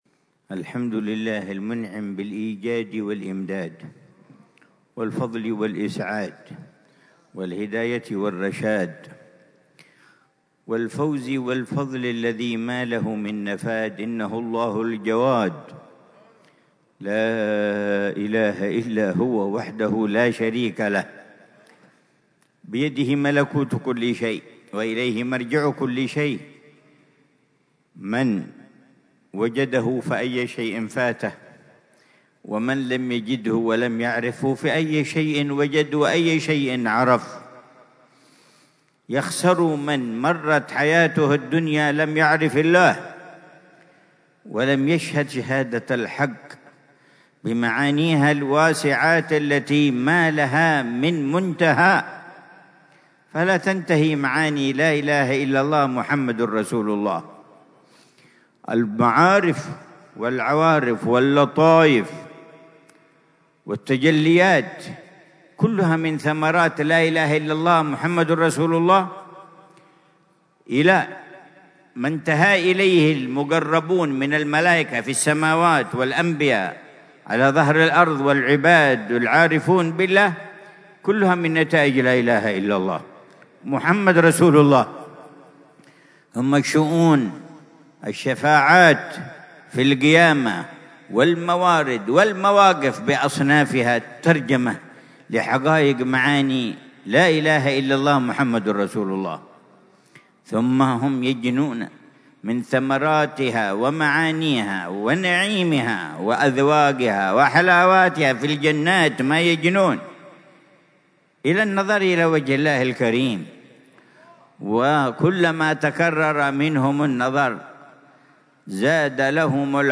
محاضرة
في دار المصطفى بتريم